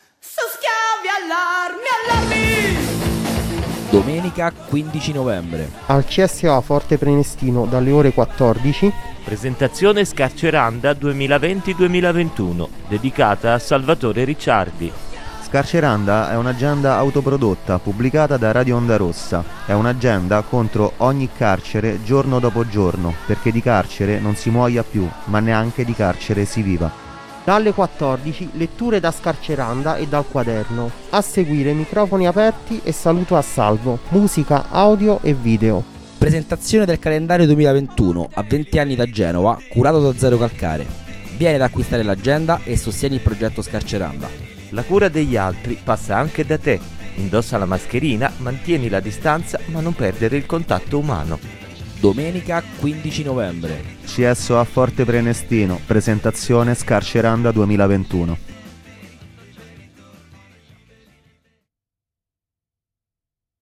Spottino